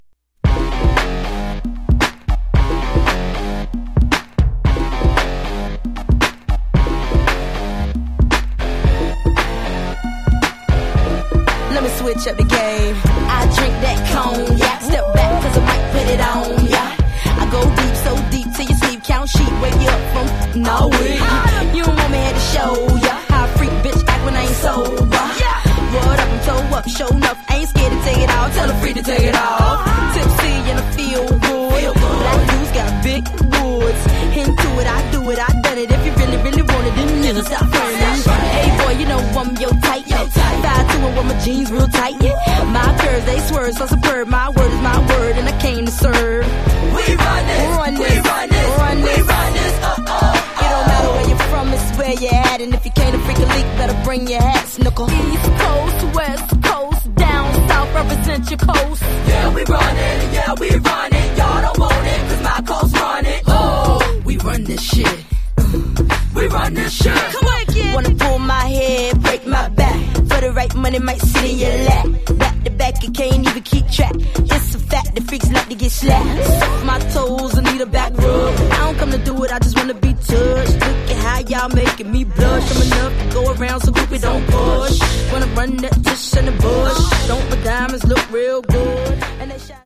115 bpm